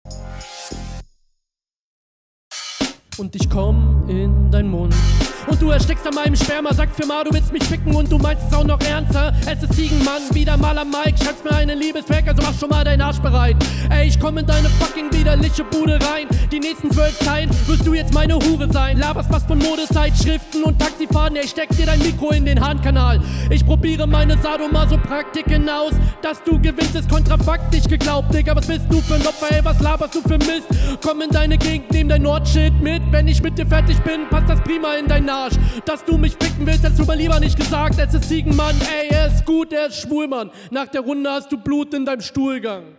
Einstieg auch bei dir nicht optimal, weil es stimmlich dünn und auch etwas unbeholfen/holprig klingt.
schön agressive attitüde. schräge runde auf jeden fall! du wehrst dich hart gegen die vorlage, …